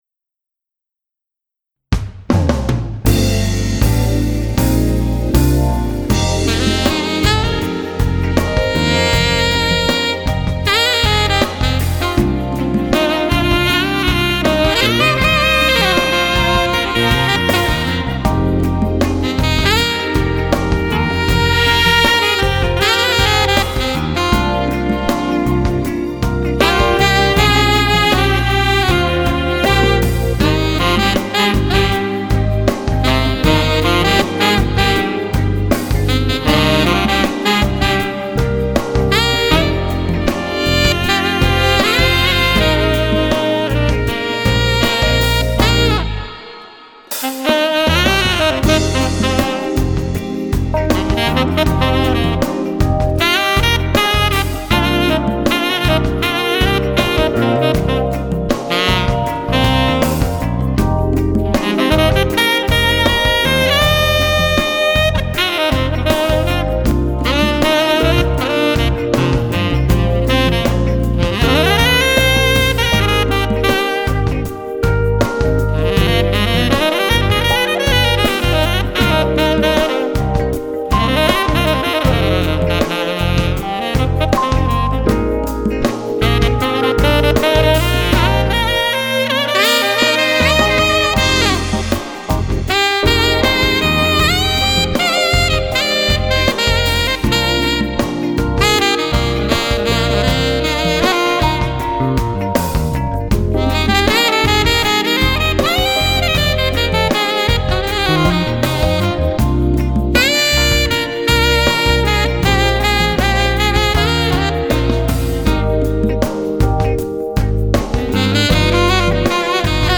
It produces a brighter and larger sound than the VI, and is designed to make more sound with little blowing.
Features and Tone: Bright, Powerful, has an edge (like metal mouthpieces)
Style: Fusion, Jazz, Pop
Alto/Tenor/Baritone